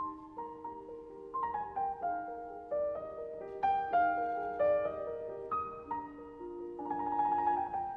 Orchestre : 1 flûte, 2 hautbois, 2 bassons, 2 cors en Ut, 2 trompettes en Ut, 2 timbales (Ut et Sol), quatuor.
5. 2e Thème du piano (Mes. 147, env. 4'29'')
(Mes. 160, env. 4'52'') Le thème se brise sur une longue gamme chromatique, soutenue discrètement par les vents et les cordes.